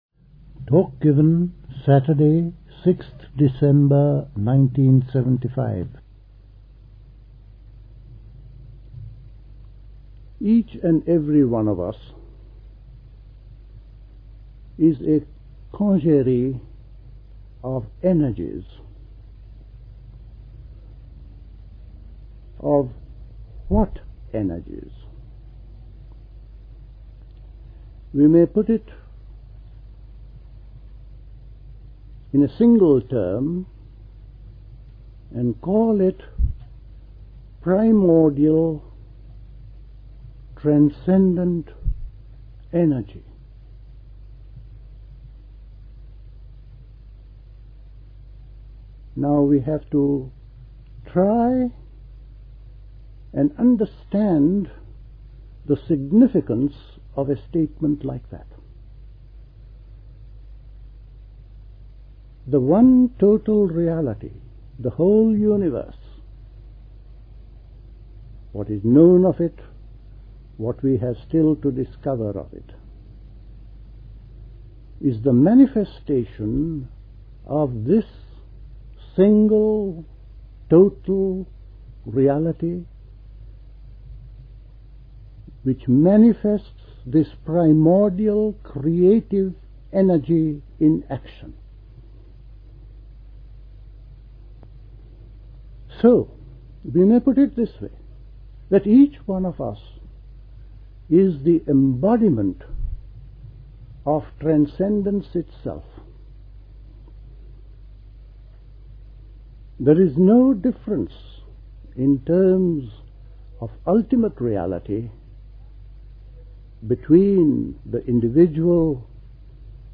Talk